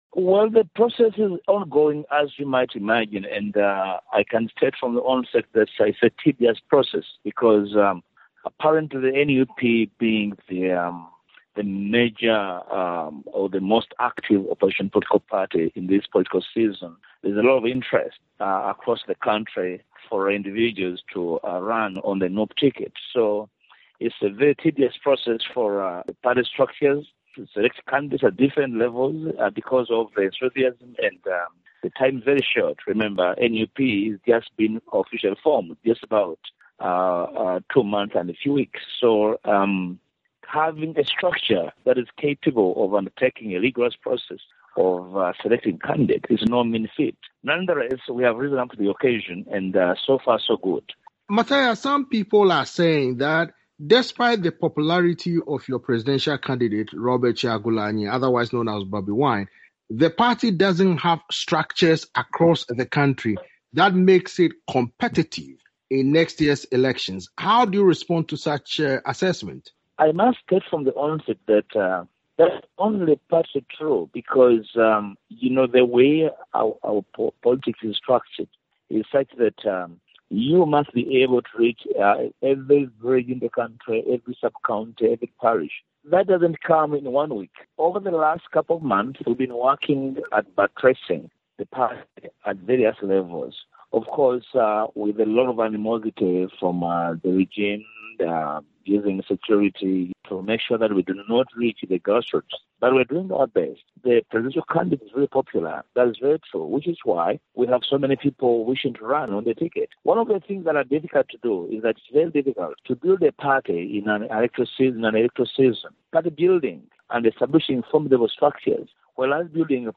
spoke with opposition leader Mathias Mpuga about efforts to unite.